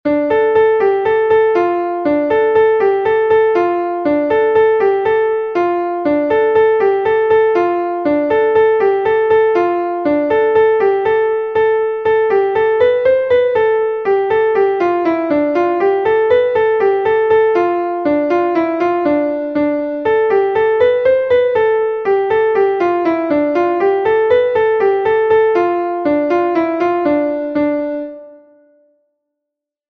Laridé de Bretagne